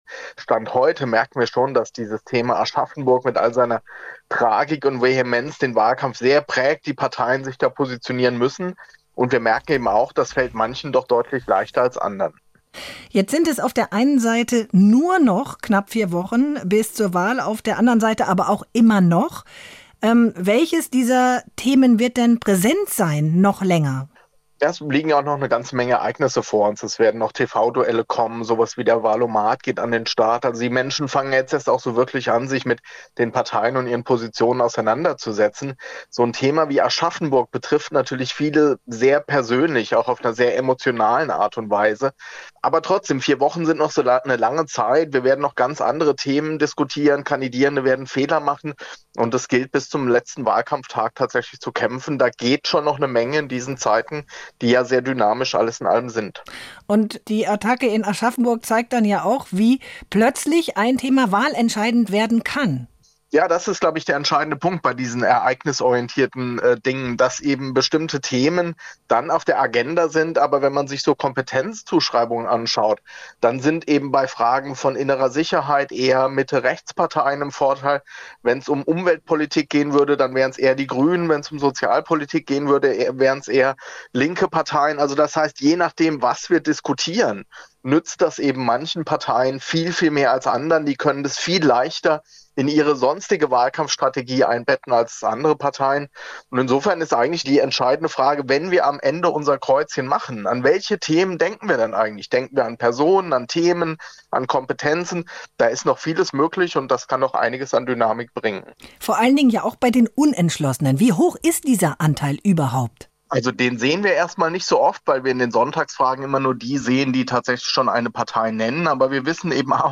Gespräch mit dem Wahlforscher